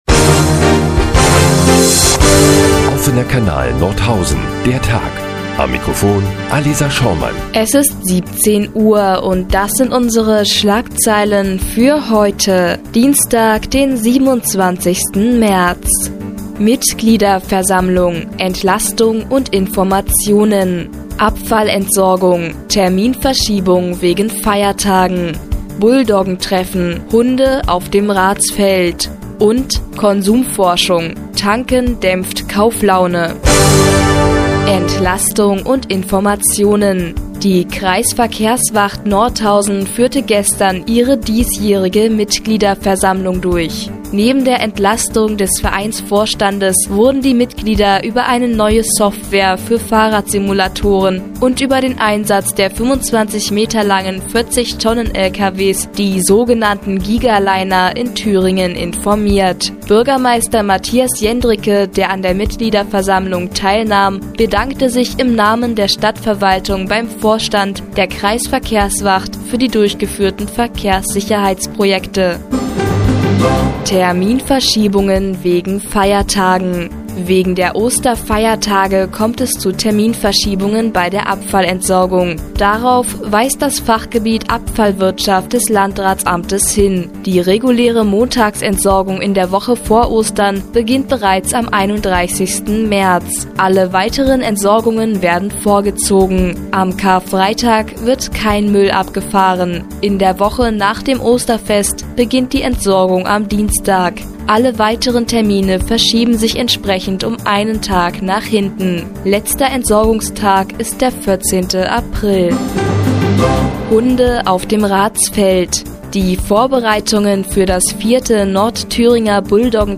27.03.2012, 17:00 Uhr : Seit Jahren kooperieren die nnz und der Offene Kanal Nordhausen. Die tägliche Nachrichtensendung des OKN ist nun auch in der nnz zu hören.